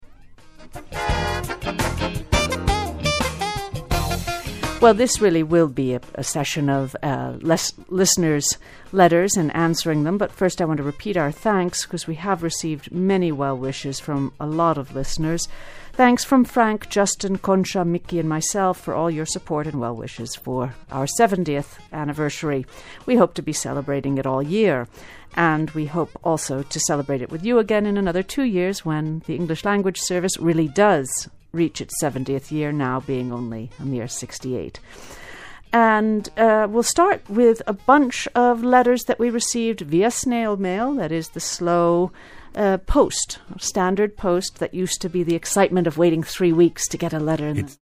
Here you will find two listening lessons based on the same snippets from an English Language Broadcast on the Spanish National Radio station.